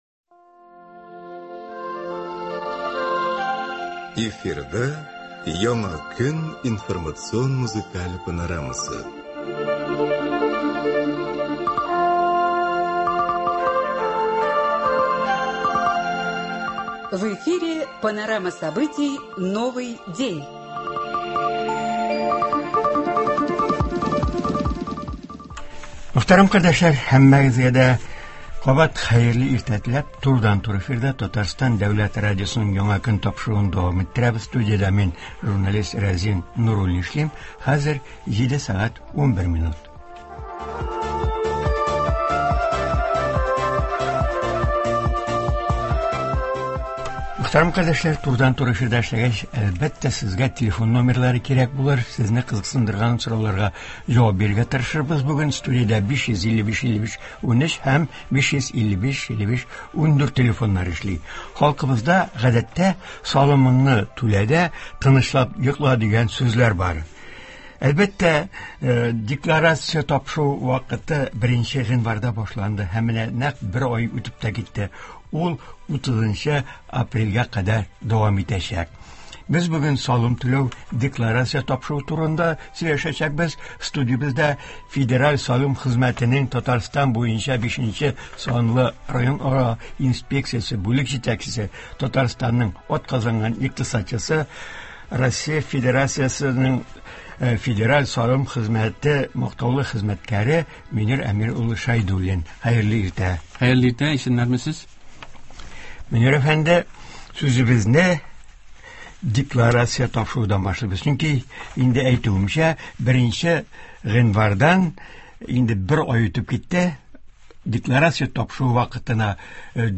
Туры эфир (31.01.24)